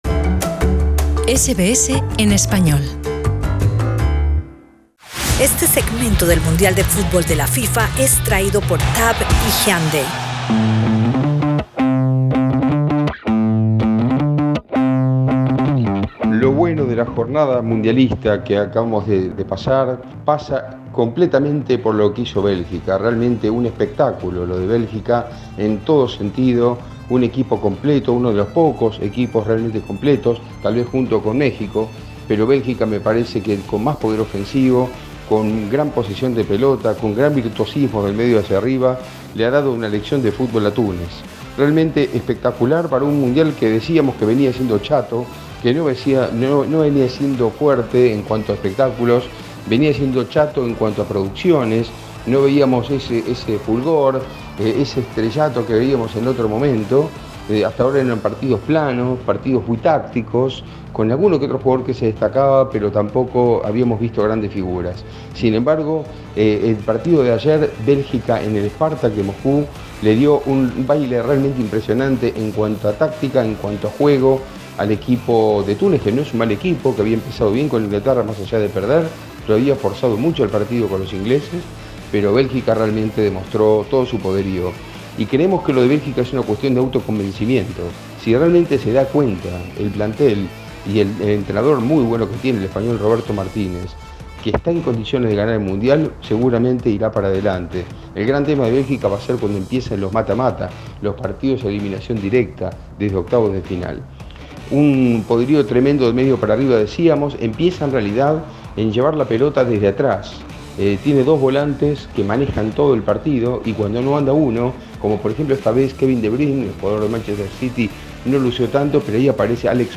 Escucha arriba en nuestro podcast el análisis deportivo